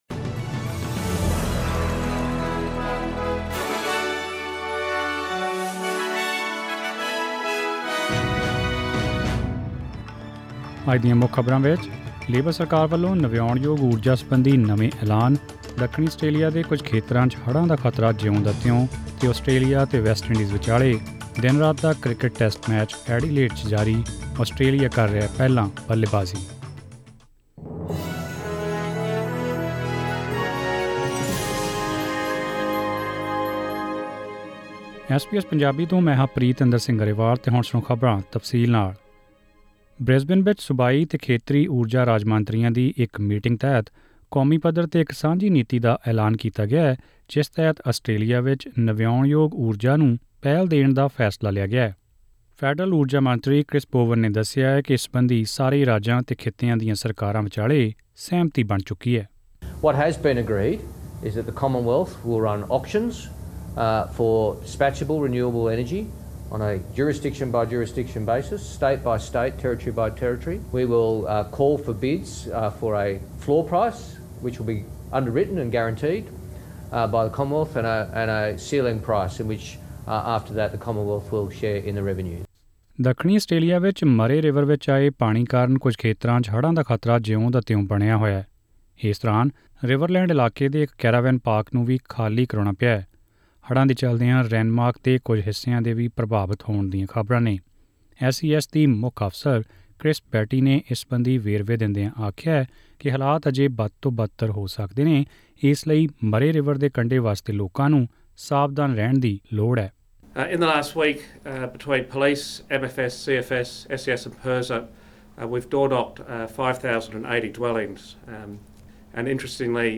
Presenting the major national and international news stories of today; sports, currency exchange details and the weather forecast for tomorrow. Click on the audio button to listen to the full bulletin in Punjabi.